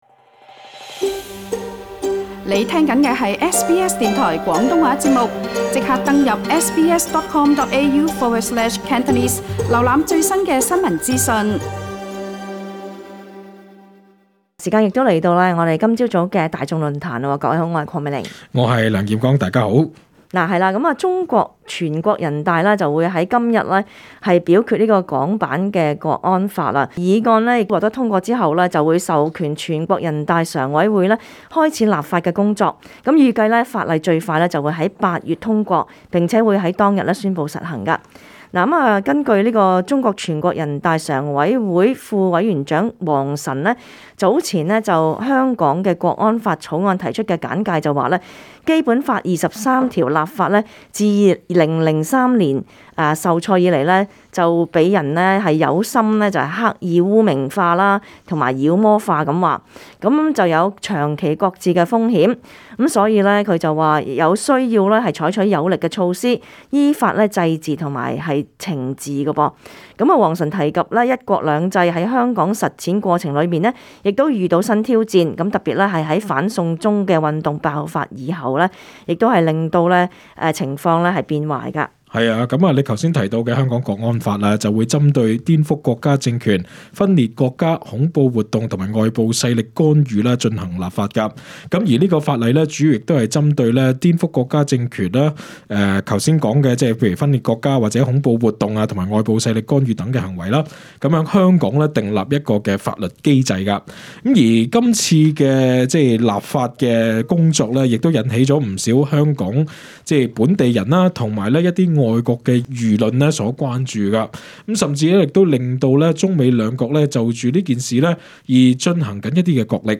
本節目內嘉賓及聽眾意見並不代表本台立場 READ MORE 【人傳人】如何可以自保免受威脅？